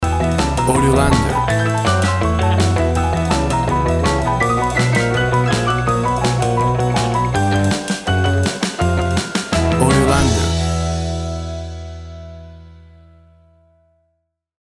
Sounds of blues and rock and roll from the 50’s.
Tempo (BPM) 160